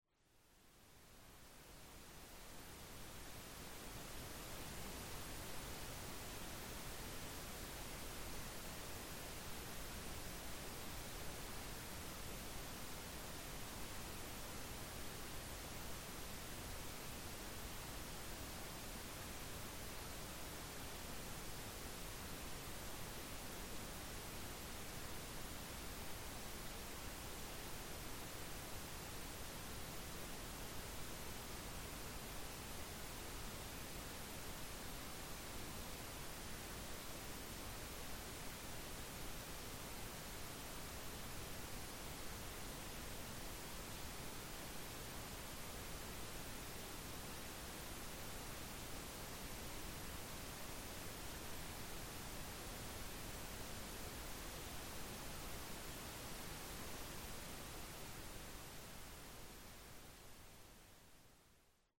Calming nature recordings and ambient soundscapes.
Wind Through the Trees
Duration: 1:02 · Type: Nature Recording · 128kbps MP3
Wind_Through_Trees.mp3